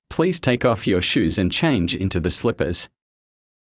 ﾌﾟﾘｰｽﾞ ﾃｲｸ ｵﾌ ﾕｱ ｼｭｰｽﾞ ｴﾝﾄﾞ ﾁｪﾝｼﾞ ｲﾝﾄｩ ｻﾞ ｽﾘｯﾊﾟｰｽﾞ